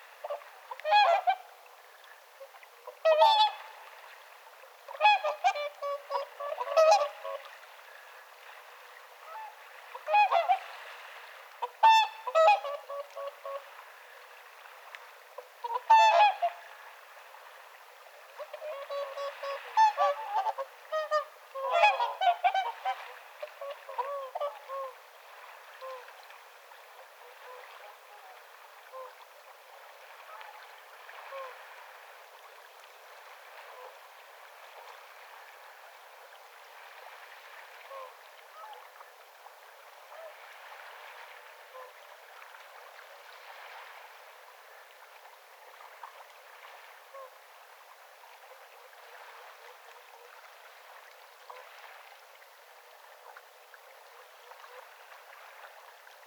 laulujoutsenen_hiljaisia_kuu-aania.mp3